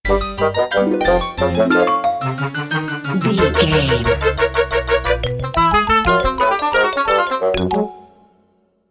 Short music, corporate logo or transition between images,
Epic / Action
Fast paced
In-crescendo
Uplifting
Ionian/Major
bright
cheerful/happy
industrial
powerful
groovy
funky
synthesiser